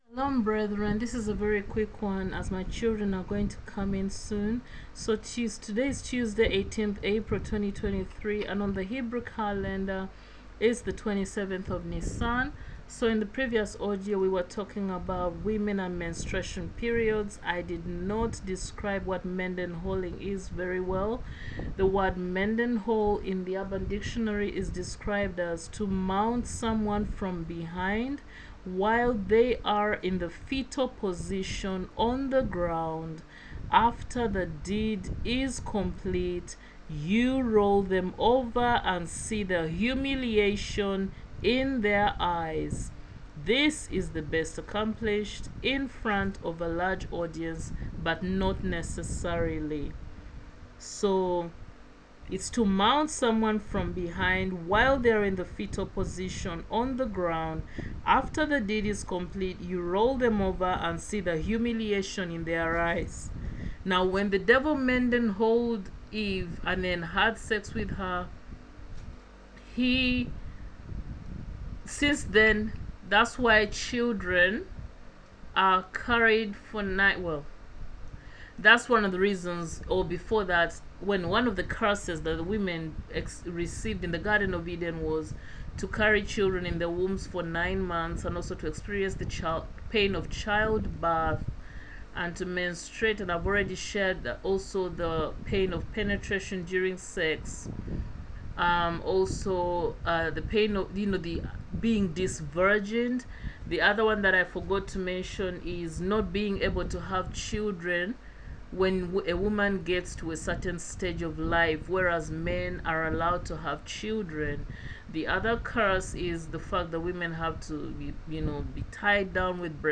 AUDIO Teaching on Various Subjects with the main focus on:🕎NISAN/AVIV 26: Anniversary of the Deaths of Joshua a.k.a Yahushua The Son of Nun and Joseph a.k.a Yahusef The Carpenter and Earthly Father Of YAHUSHUA HA MASHIACH a.k.a JESUS CHRIST!🕎2.